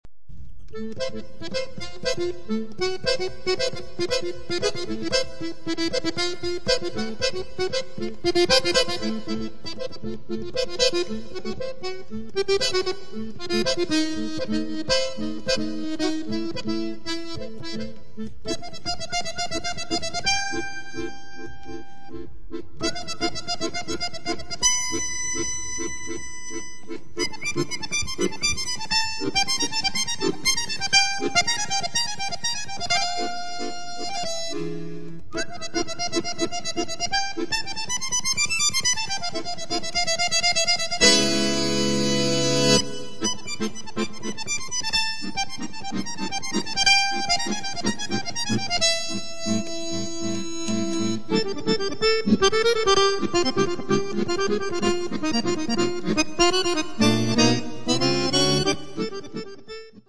Accord�on